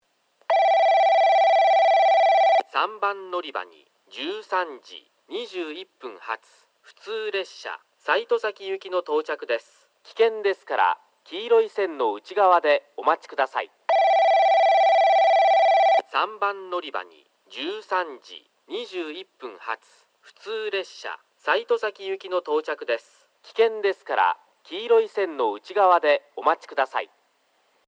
その為西戸崎、雁ノ巣行きの男声放送を聞くことができるようになり、さらには香椎行きの放送も聞けるのが大きな特徴です。
3番のりば接近放送（西戸崎行き）
※香椎線ホームの接近ベルは省略しております。